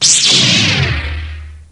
saberSwitchOn.wav